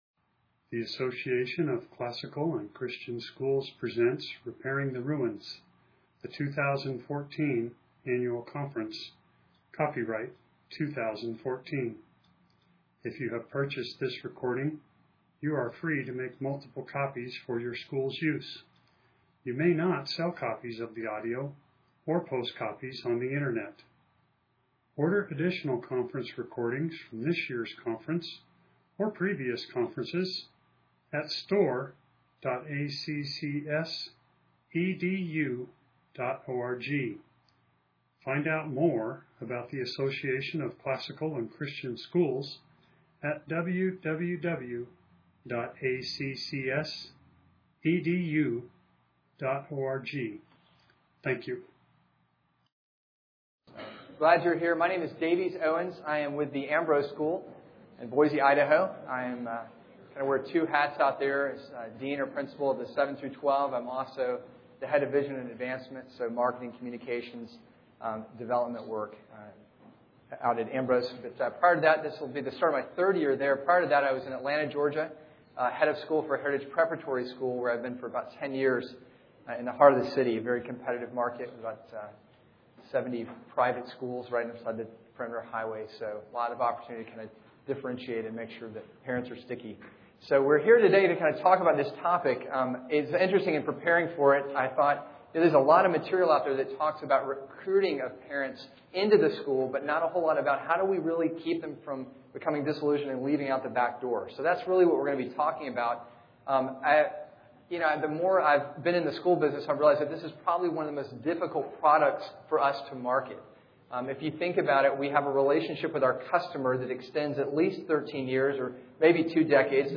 2014 Leaders Day Talk | 2014 | Marketing & Growth